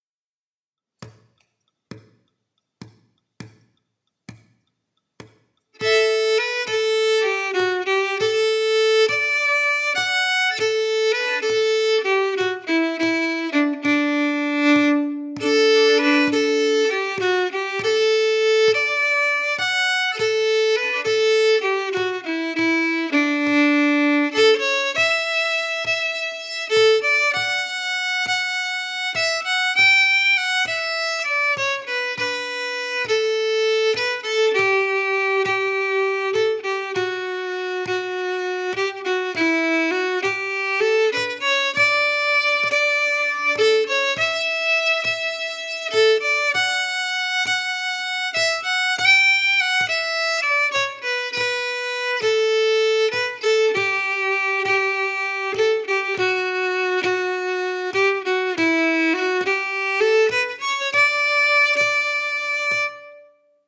Key: D
Form: Pols (Norwegian polska)
Played slowly for learning
Genre/Style: Norwegian pols (polska)
Fanteladda-slow.mp3